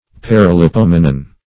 Paralipomenon \Par`a*li*pom"e*non\, n. pl.